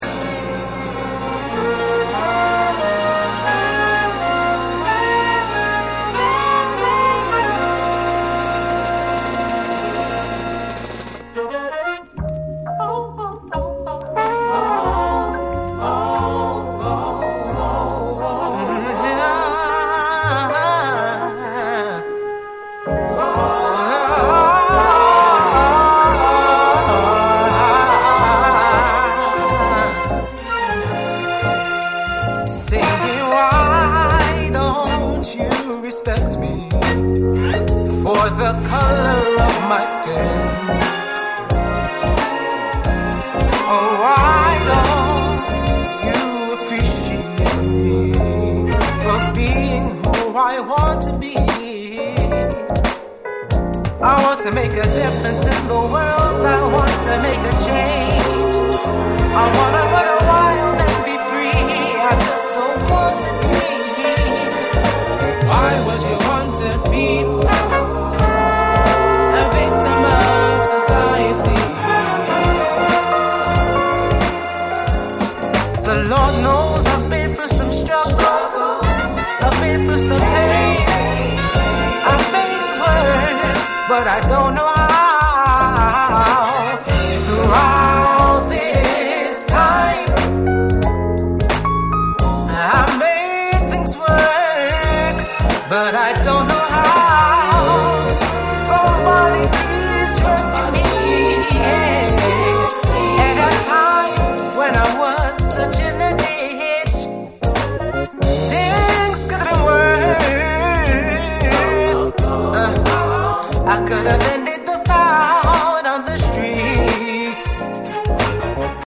BREAKBEATS / SOUL & FUNK & JAZZ & etc